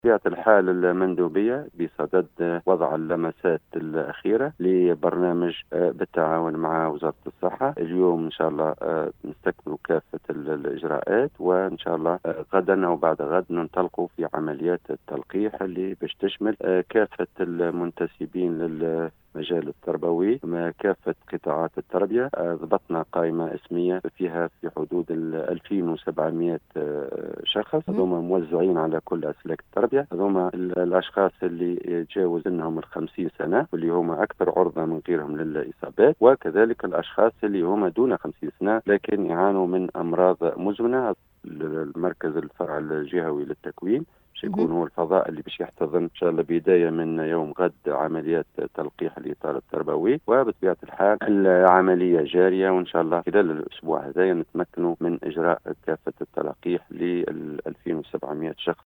أفاد المندوب الجهوي للتربية بالقصرين المنصف القاسمي في تصريح لاذاعة السيليوم أف أم اليوم الأحد 23 ماي 2021  أنّ الوضع الوبائي بالوسط المدرسي مستقر حيث لم يشهد ارتفاعا في نسق الإصابات ، و أنّ الجهود متواصلة منذ مفتتح السّنة الدّراسيّة للحفاظ على سلامة الإطار التربوي و التّلاميذ